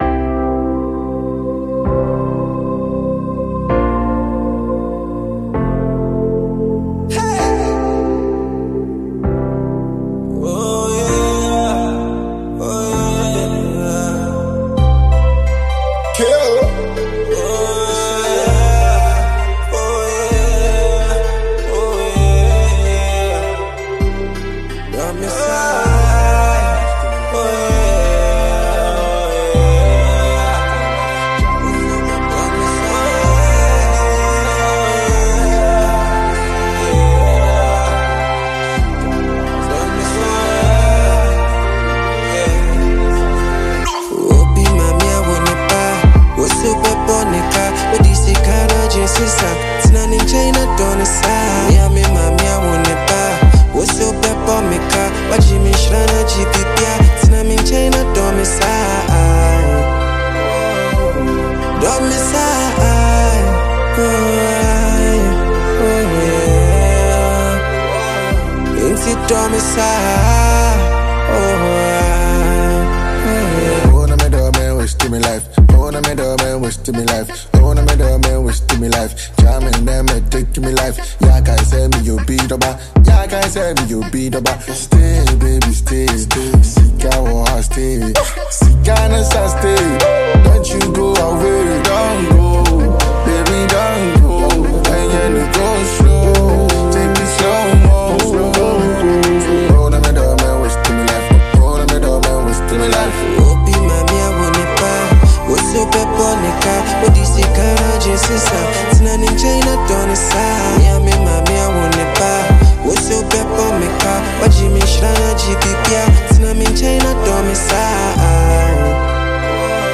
rhythmical sound and style